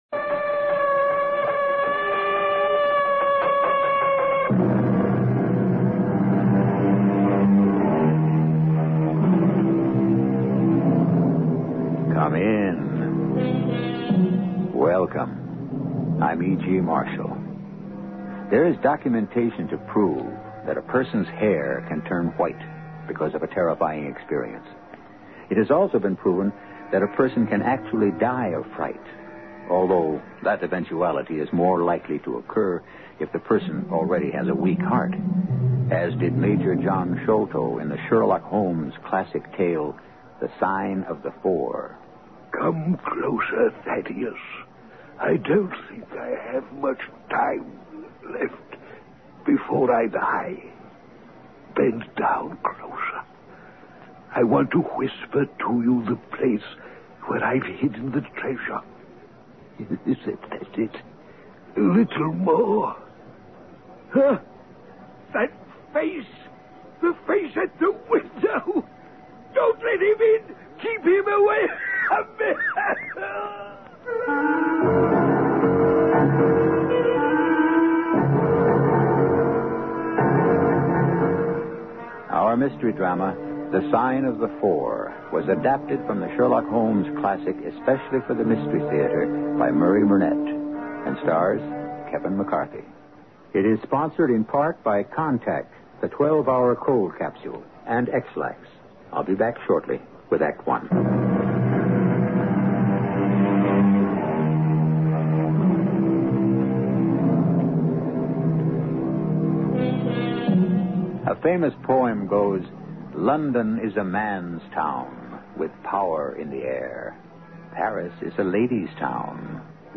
Radio Show Drama with Sherlock Holmes - The Sign Of Four 1977